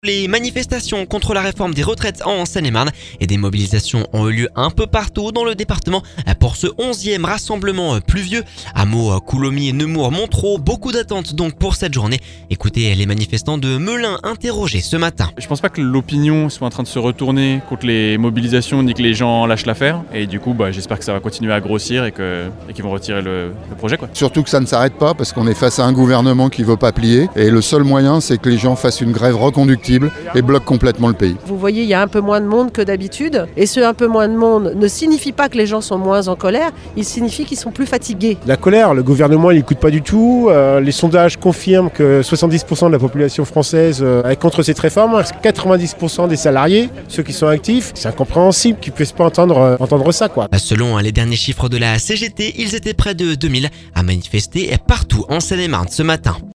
Ecoutez les manifestants de Melun interrogés ce matin…